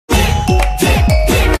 drift-drift-drift-sound-effect.mp3